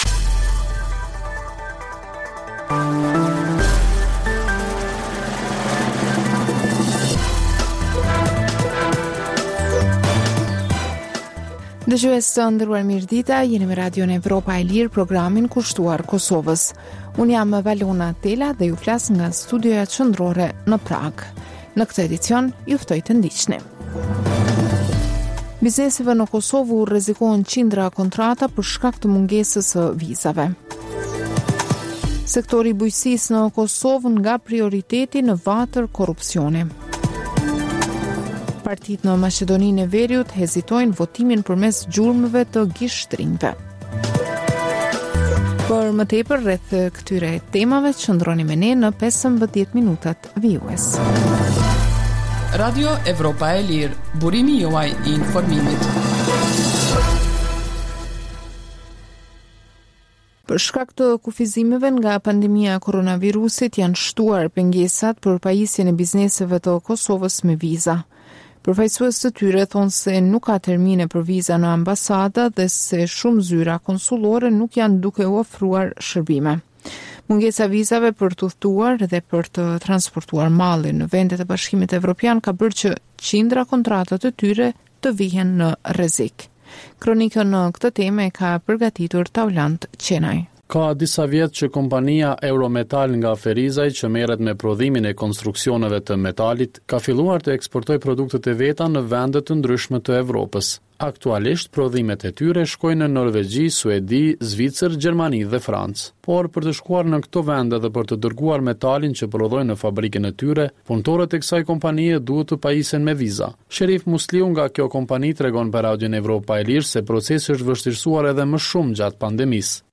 Emisioni i mesditës fillon me buletinin e lajmeve që kanë të bëjnë me zhvillimet e fundit në Kosovë, rajon dhe botë.
Emisioni i mesditës në të shumtën e rasteve sjellë artikuj nga shtypi perendimor, por edhe intervista me analistë të njohur ndërkombëtar kushtuar zhvillimeve në Kosovë dhe më gjërë.